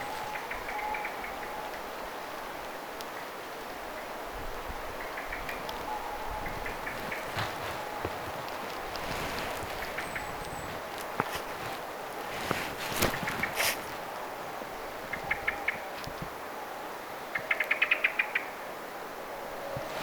tuollaista keltanokkarastaslinnun ääntelyä
tuollaista_keltanokkarastaslinnun_aantelya.mp3